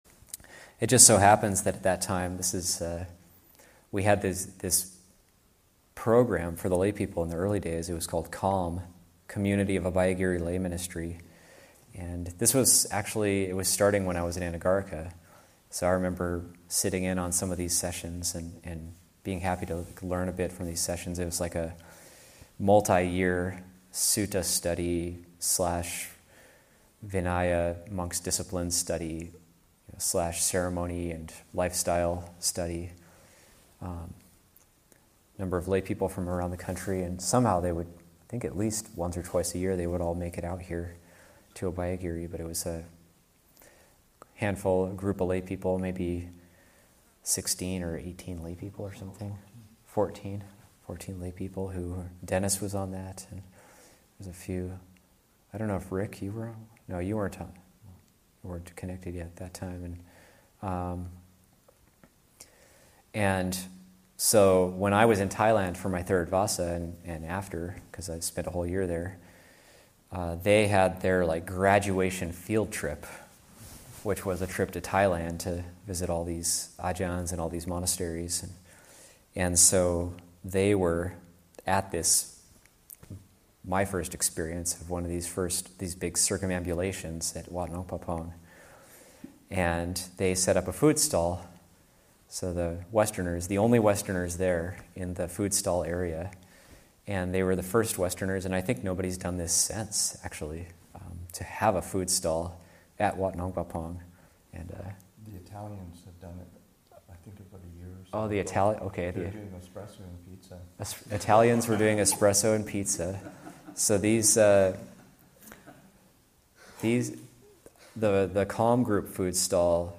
Recollection: The Community of Abhayagiri Lay Ministers sets up a food stall at Wat Pah Pong.